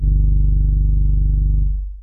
LOWEST SUB 1.wav